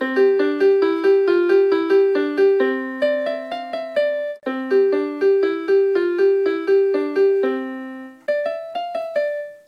This is actually supposed to be a cowboy song (bum-pa dum-pa dum-pa dum-pa dum, ridin’ over the trail…) But it works nicely as a ringtone.